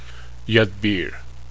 Punjabi Pronunciation